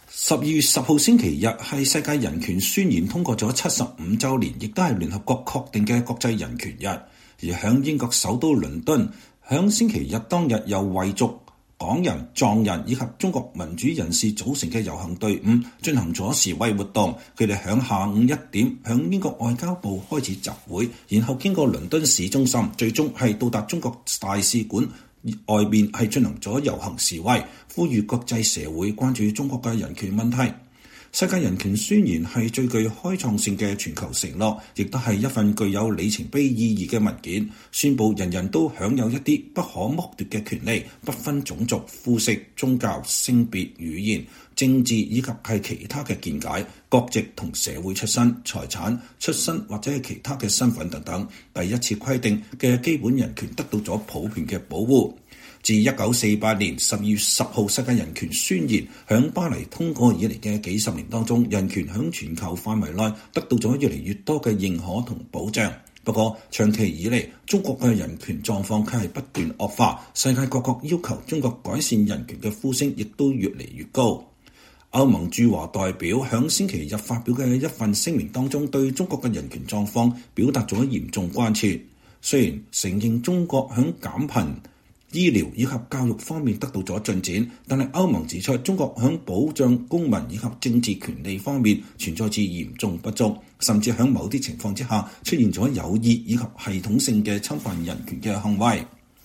12月10日，在英國外交部前，人權組織舉行抗議，呼籲國際社會關注中國人權議題。